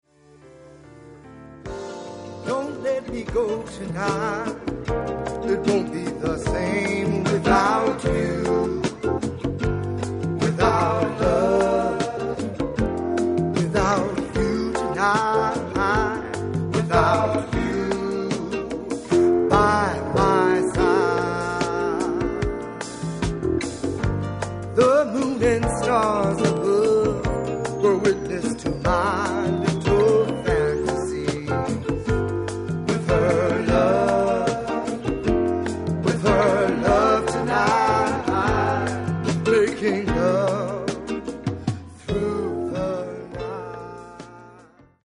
メロウなラテン・フレイヴァーとグルーヴィなR&B